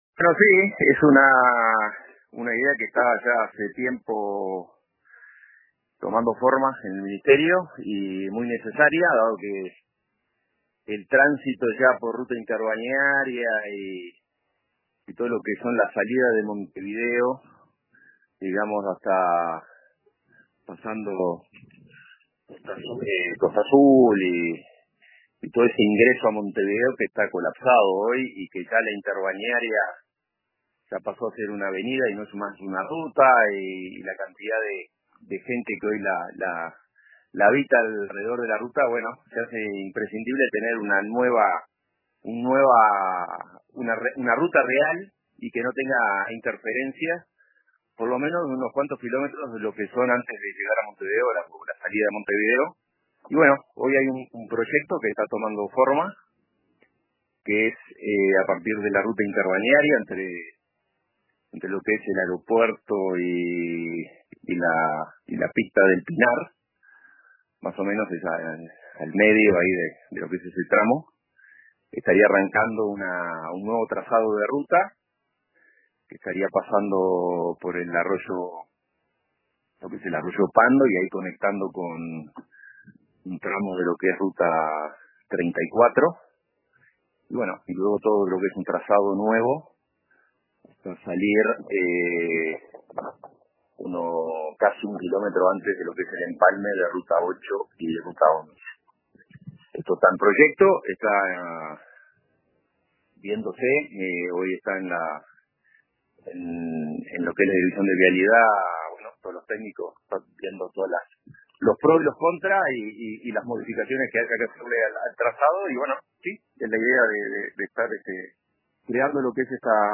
Hernán Ciganda, Director Nacional de Vialidad, compartió en una entrevista con el Informativo Central de RADIO RBC que “es una idea que lleva tiempo tomando forma en el ministerio y es sumamente necesaria debido al congestionamiento en la Ruta Interbalnearia y en todas las salidas de Montevideo, incluso más allá de Costa Azul.